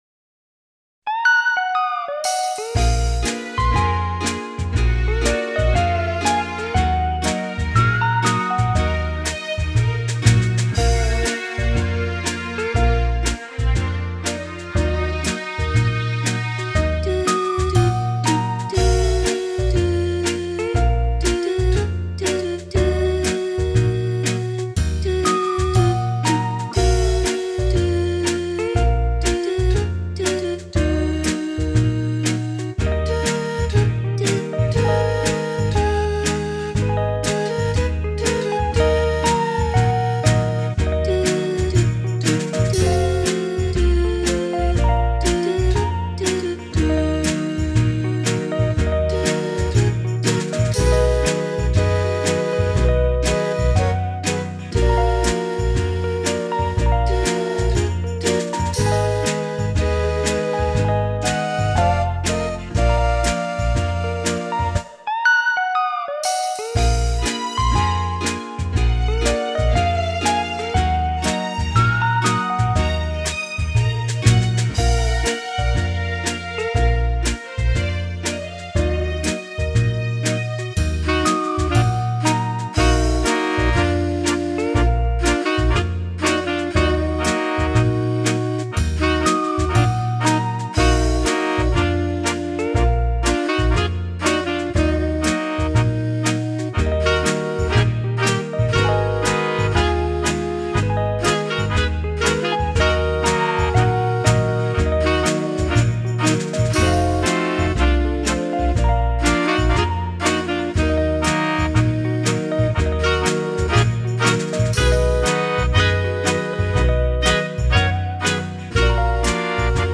Очень нежная мелодия, можно слушать и слушать.
Ваше новое хобби  убеждает, что и написать красивые  инструментальные произведения для Вас не проблема.